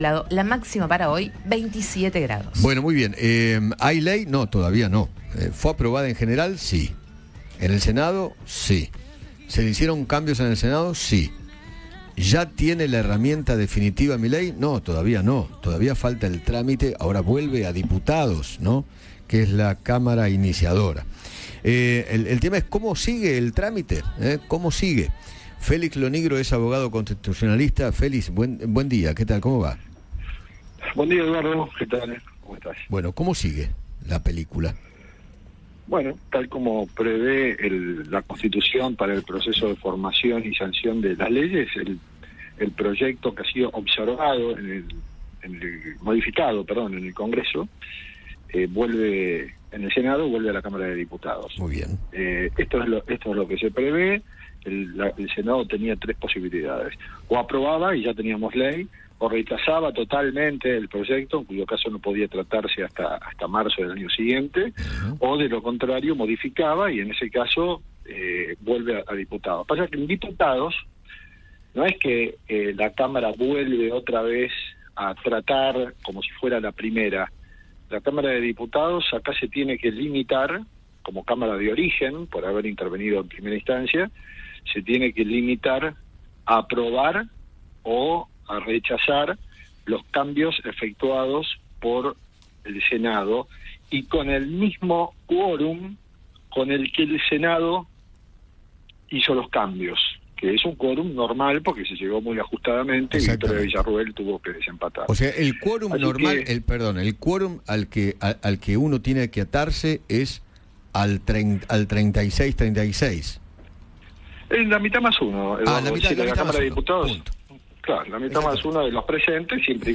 habló con Eduardo Feinmann sobre el camino que sigue a la Ley Bases, después de ser aprobada en general por el Senado.